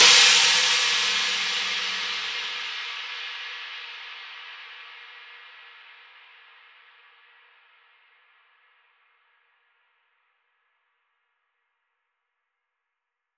edm-crash-07.wav